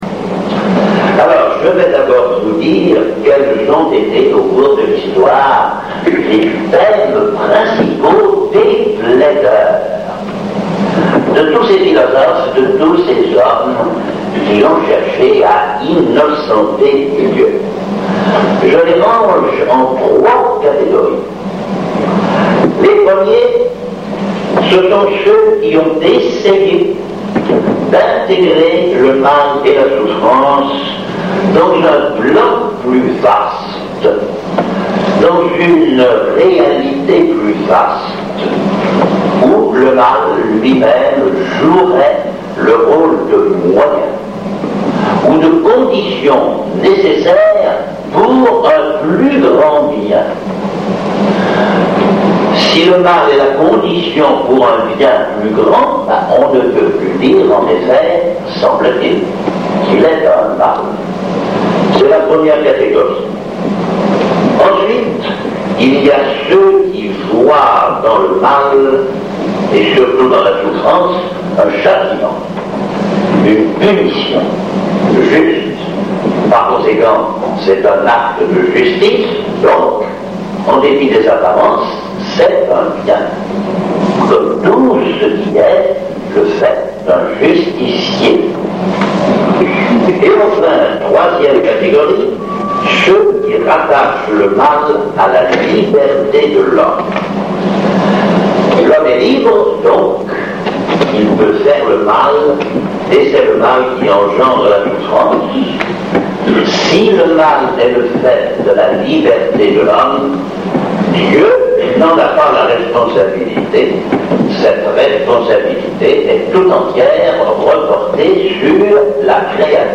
Je les enregistrais sur un « mini-cassette ». Ces merveilleux petits appareils qui avaient remplacé les gros magnétophones à bande n’offraient pas les possibilités de ceux que l’on a de nos jours. De plus j’effectuais ces enregistrements à partir de l’endroit où j’étais assis dans la salle. Mais la sonorisation n’était pas mauvaise.
Le son n’est pas très bon mais la diction du conférencier est si parfaite qu’on suit aisément ses explications.